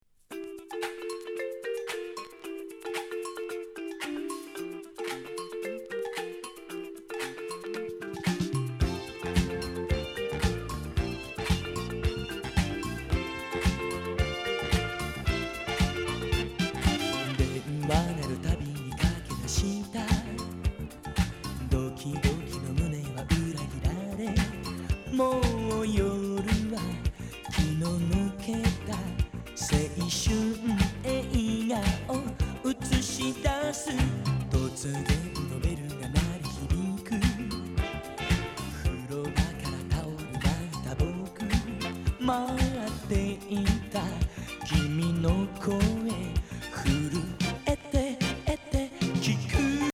トロピカル・ディスコ